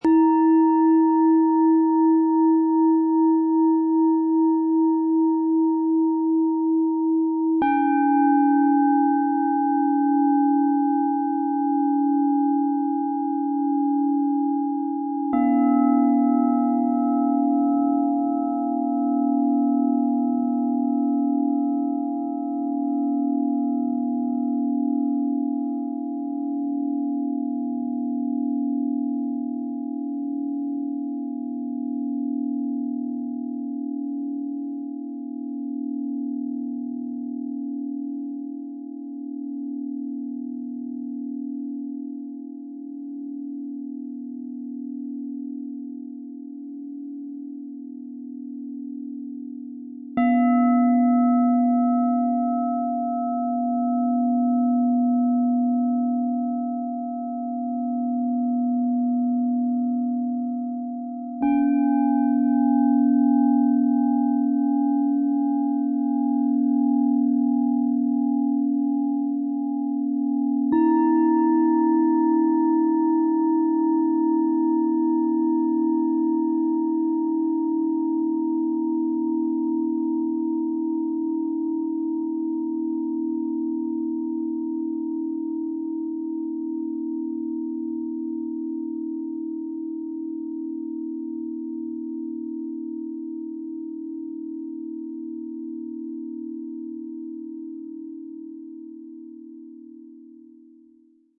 Tempel-Qualität - einmaliges Set aus 3 japanischen Tang - Klangschalen, Ø 16,4 - 24 cm, 6,51 kg
Ihr Klang ist herzlich, freundlich, verbindend, aktuell und klar.
So wird das Set rund, umfassend, klar und weit.